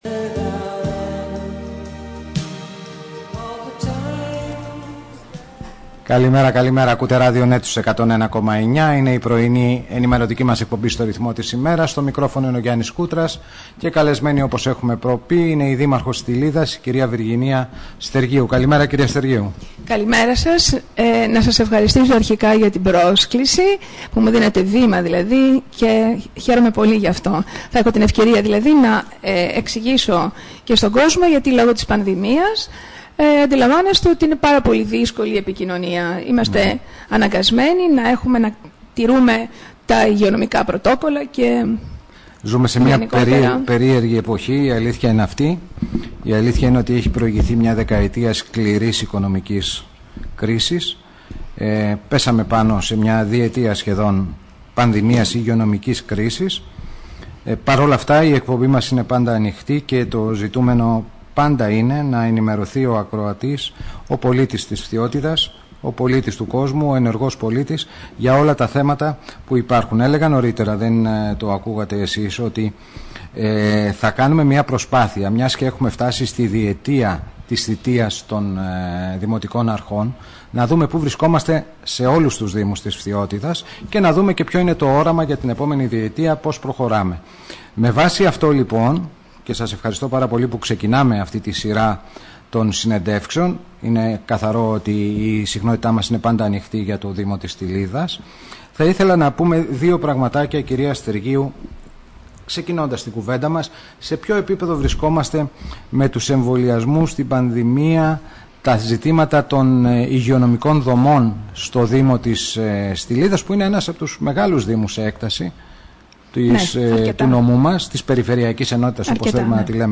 Συνέντευξη Δημάρχου κας Βιργινία Στεργίου στον Ρ/Σ RADIONET 101.9 και στην εκπομπή "Στο ρυθμό της ημέρας".
dimarxos_stilida1.mp3